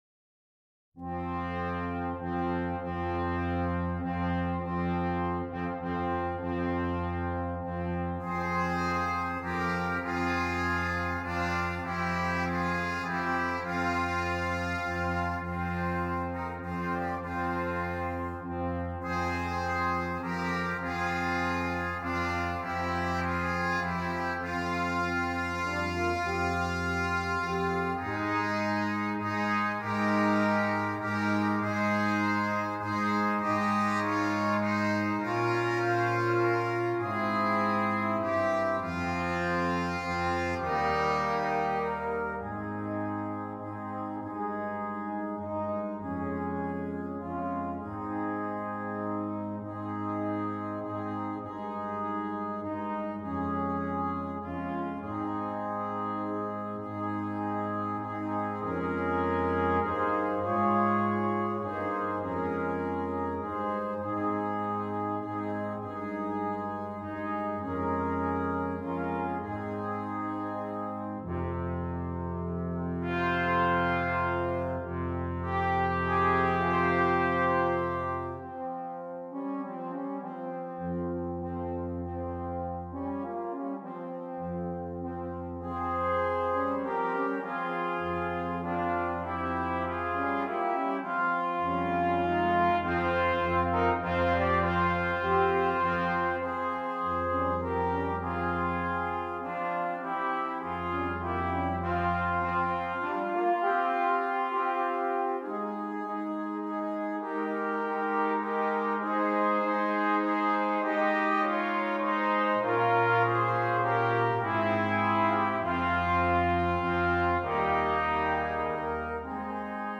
Christmas
Brass Quintet